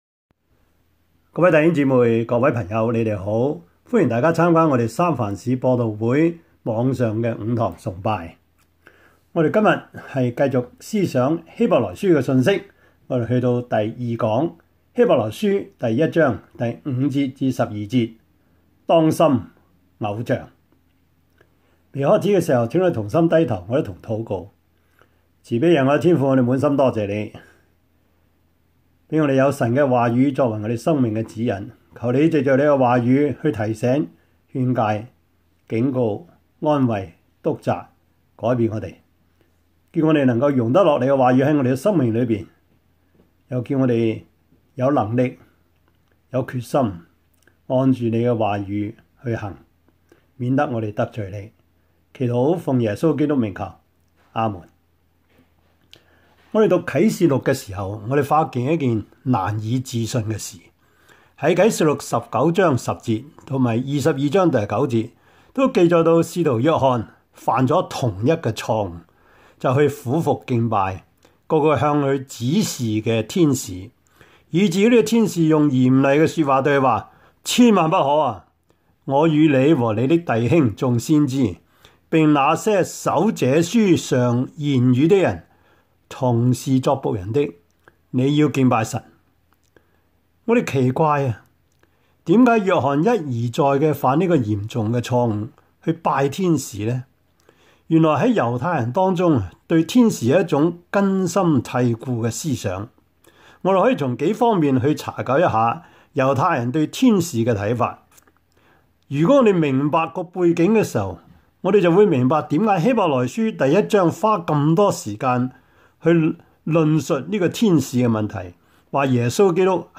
Service Type: 主日崇拜